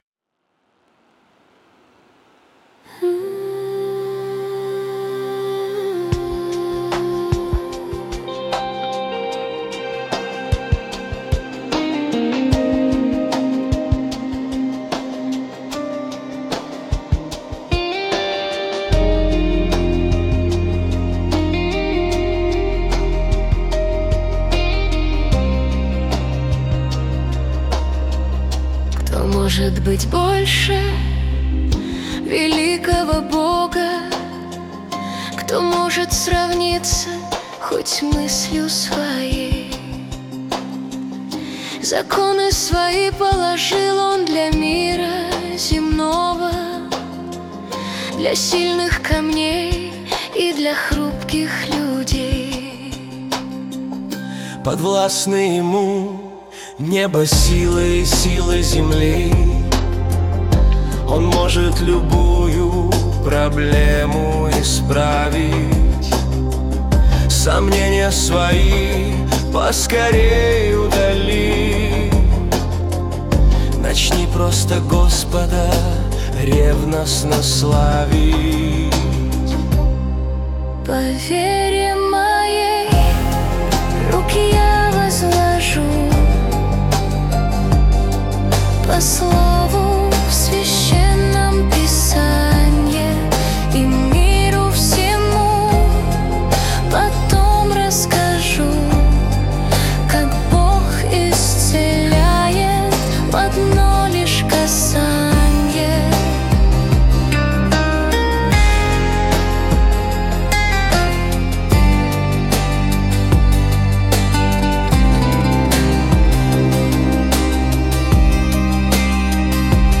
песня ai
237 просмотров 1001 прослушиваний 88 скачиваний BPM: 75